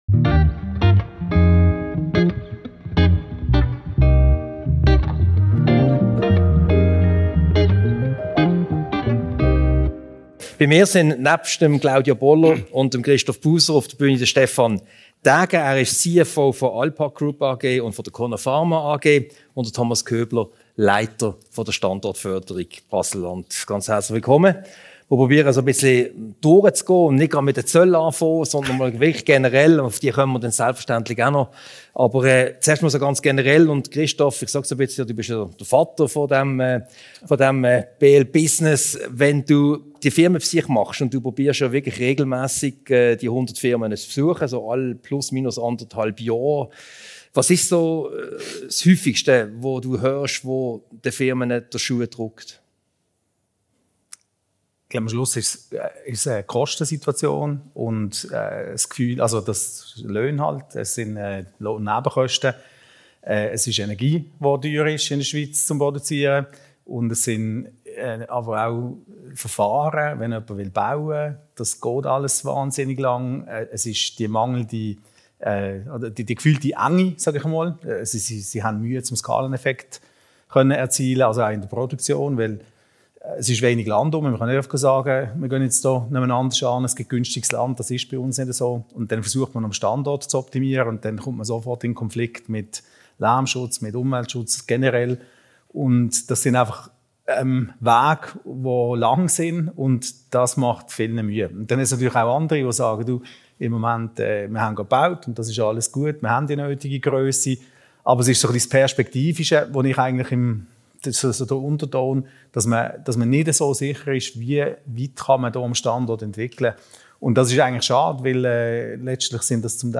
Ein Gespräch über die aktuellen Herausforderungen für die Unternehmen im Kanton, über die Voraussetzungen für anhaltenden Erfolg sowie über veränderte Rahmenbedingungen wie die US-Zölle und deren Auswirkungen. Diese Podcast-Ausgabe wurde anlässlich der BL Business Night vom 2. September im Auditorium vom Haus der Wirtschaft HDW aufgezeichnet.